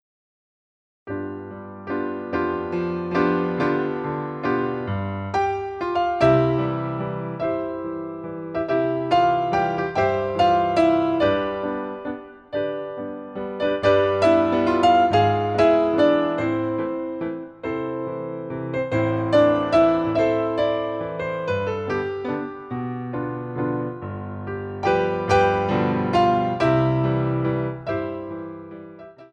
PIROUETTES EN DIAGONAL II